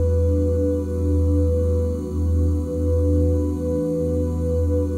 OOHE SUS13.wav